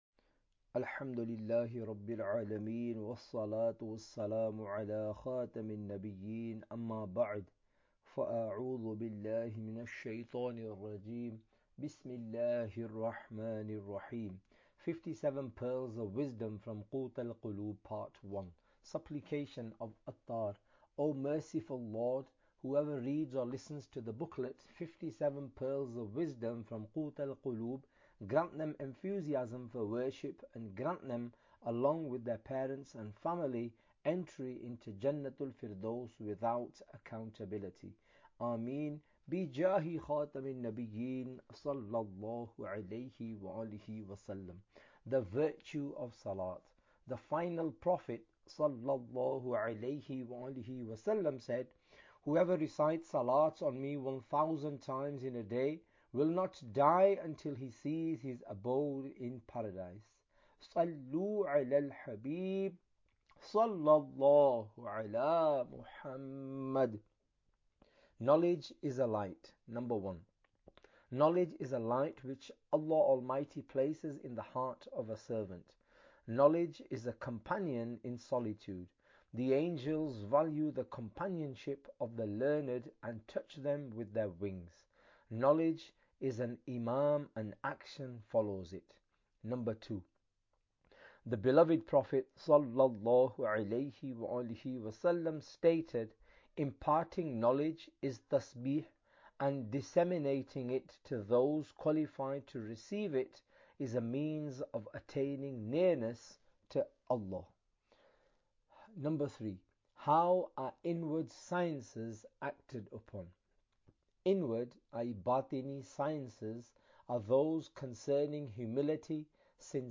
Audiobook – 57 Pearls Of Wisdom From Qut Al Qulub – Part 01 (English)